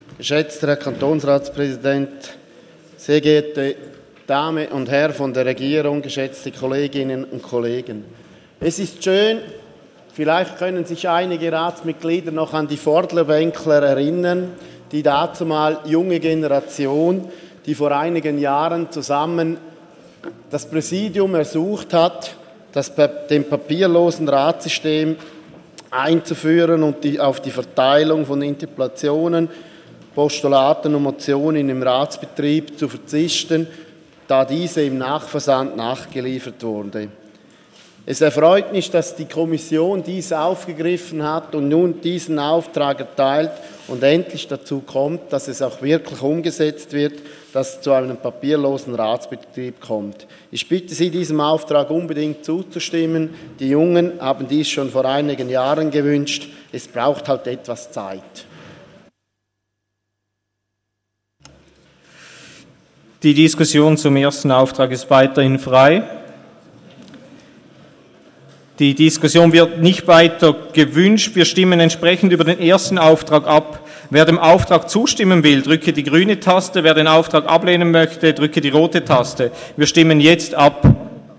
Session des Kantonsrates vom 12. und 13. Juni 2017
(im Namen der CVP-GLP-Fraktion): Auf die Vorlage ist einzutreten.